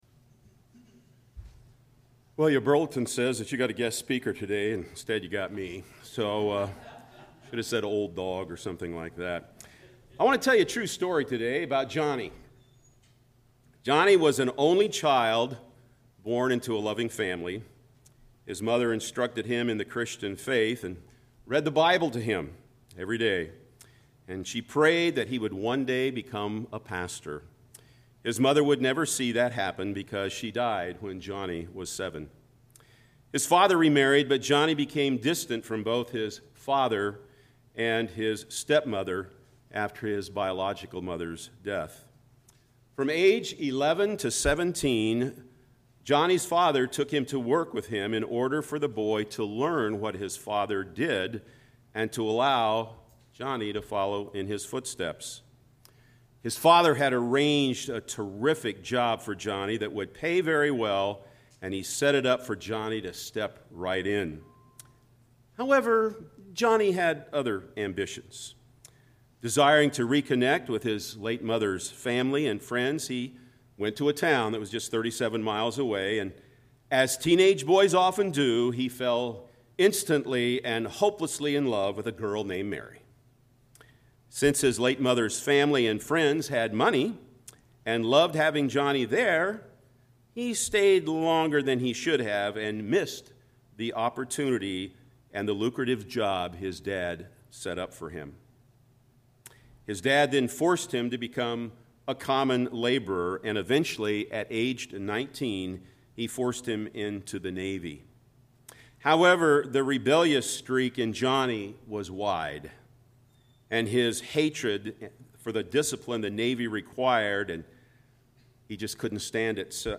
| PVBC Sermons